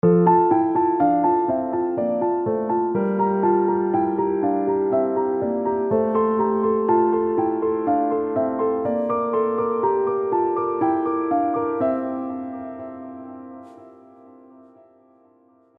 Here’s the same figure in F major, for example:
(FYI — it’s played an octave higher than written.